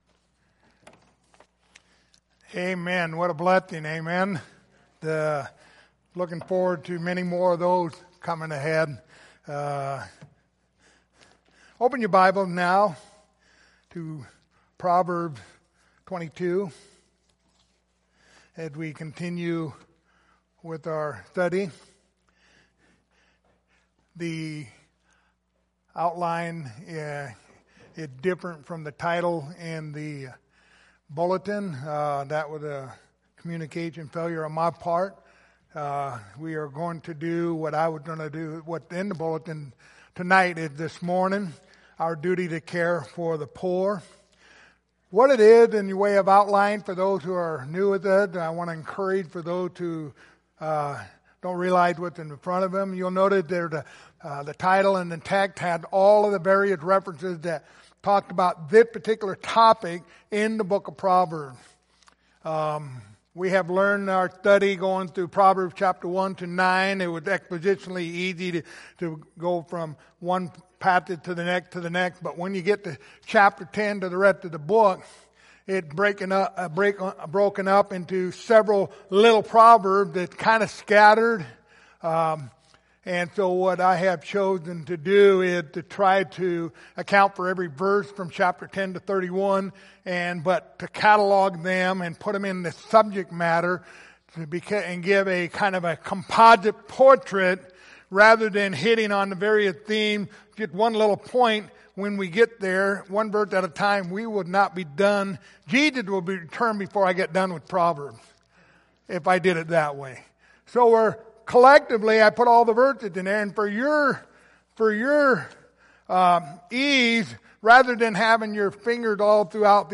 Passage: Proverbs 14:21 Service Type: Sunday Morning